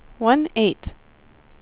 number_4.wav